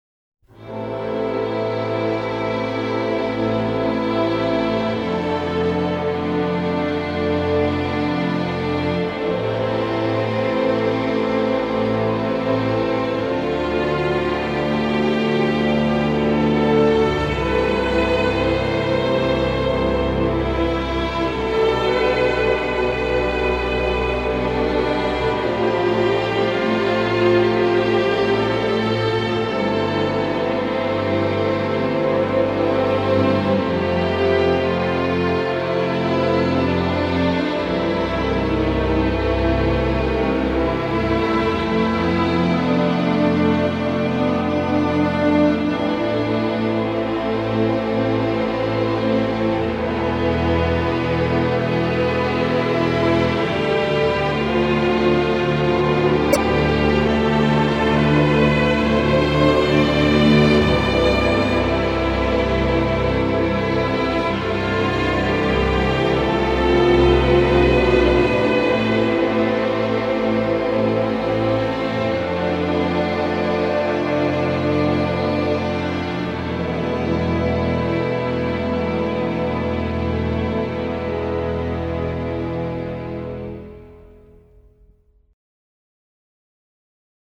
hermosa partitura clasicista